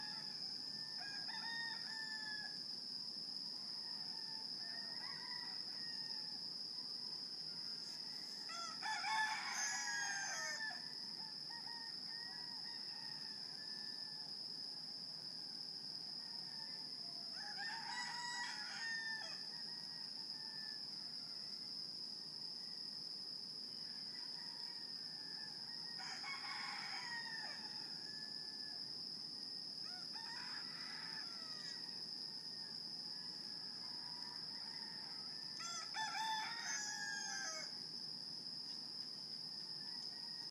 Hawaii - chickens on Christmas Day